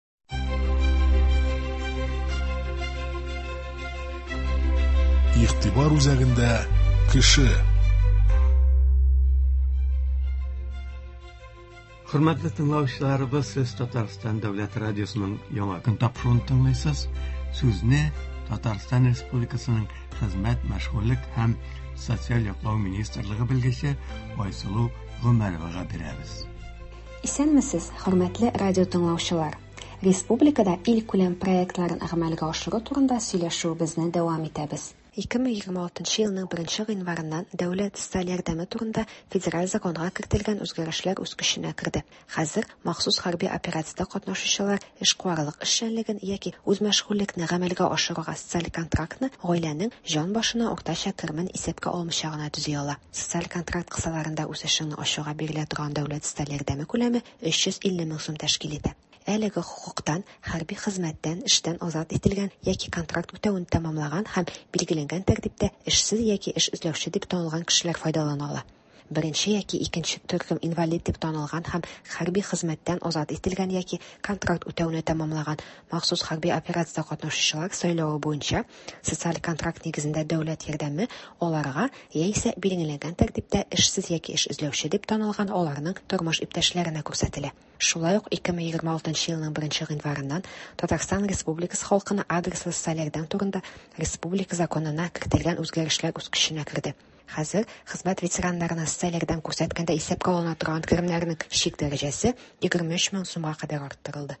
Туры эфир (19.01.26)
— «Яңа Чишмә» районы белән телефон элемтәсенә керү.